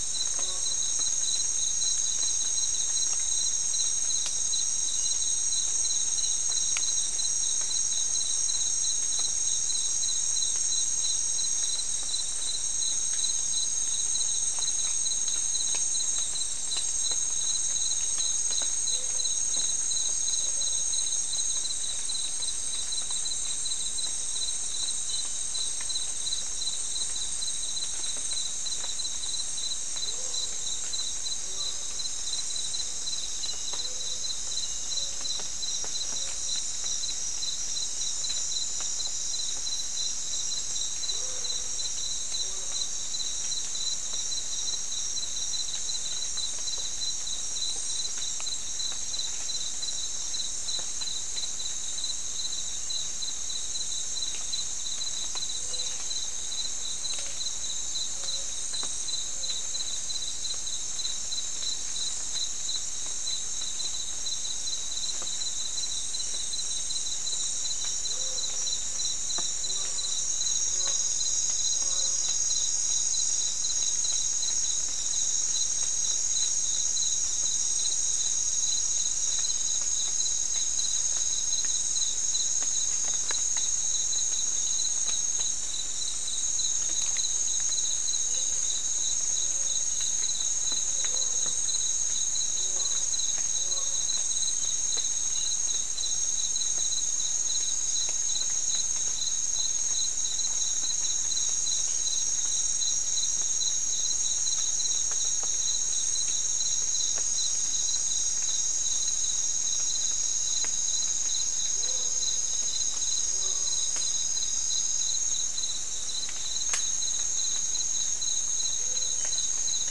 Soundscape Recording Location: South America: Guyana: Mill Site: 3
Recorder: SM3